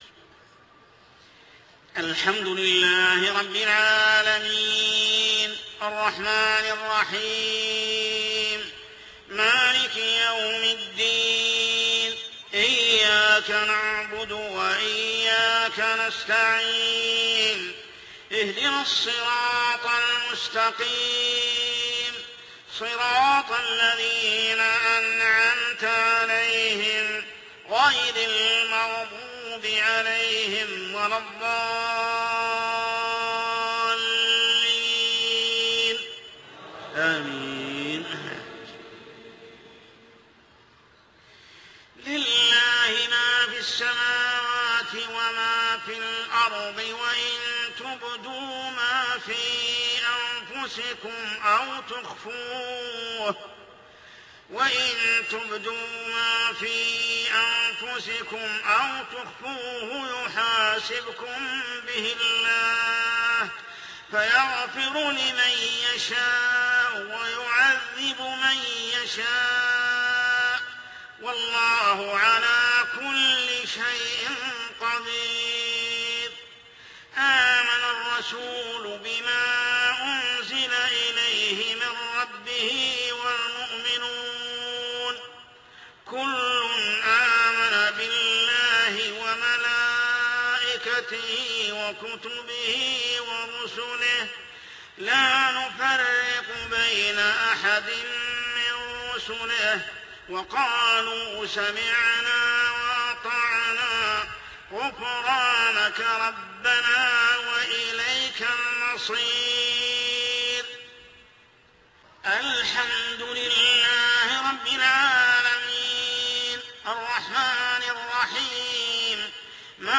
صلاة العشاء عام 1428هـ من سورة البقرة 284-286 | Isha prayer Surah Al-Baqarah > 1428 🕋 > الفروض - تلاوات الحرمين